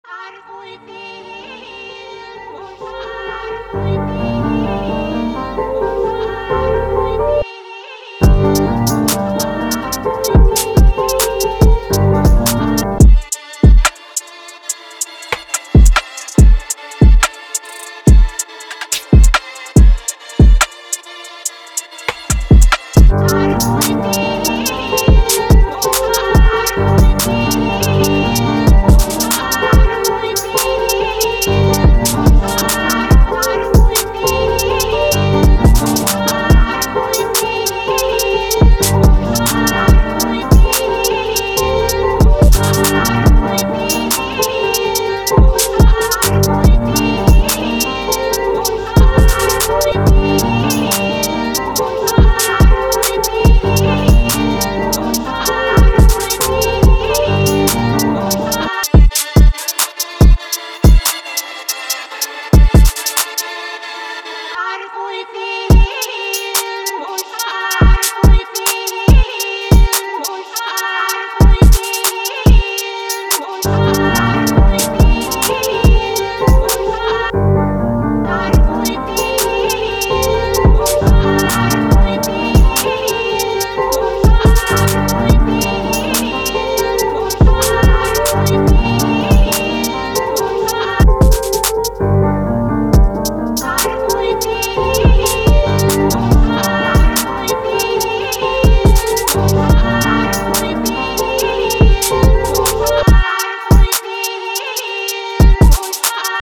Жанр: Rap, drill, hyperpop и другие